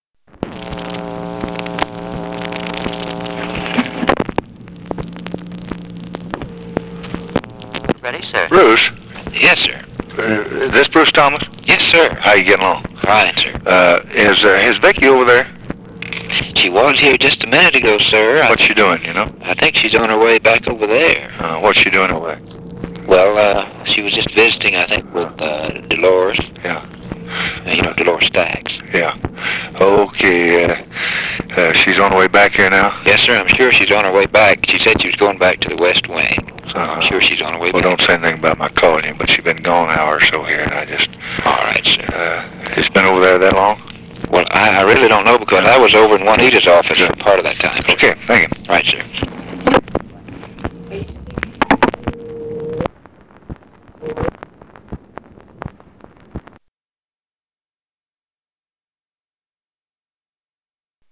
Secret White House Tapes | Lyndon B. Johnson Presidency